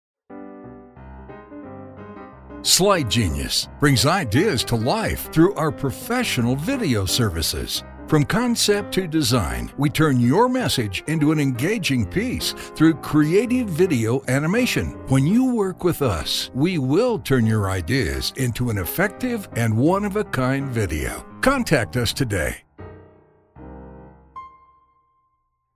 Voice over examples
Male Voice 2